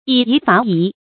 以夷伐夷 yǐ yí fá yí
以夷伐夷发音
成语注音ㄧˇ ㄧˊ ㄈㄚˊ ㄧˊ